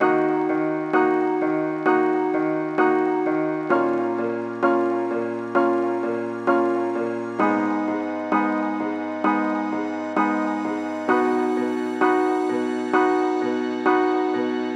Twenty Eight Piano (130 BPM D# Minor).wav